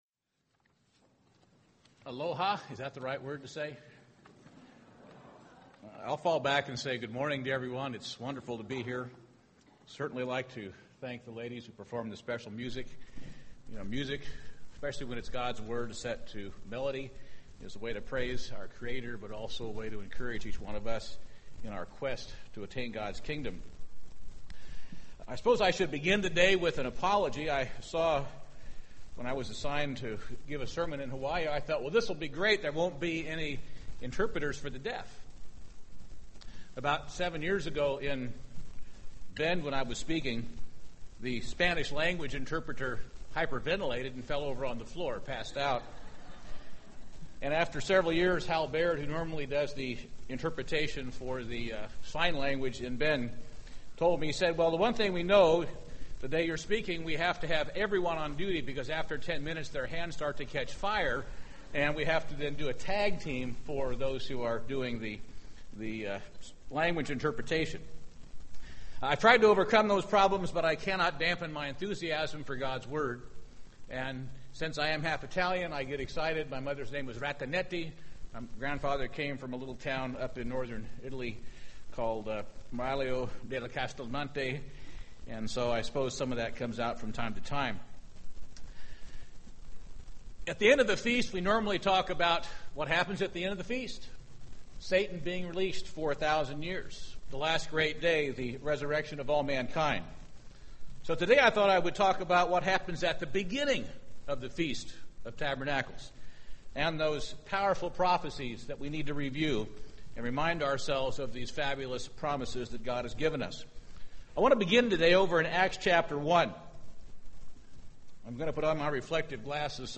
This sermon was given at the Maui, Hawaii 2011 Feast site.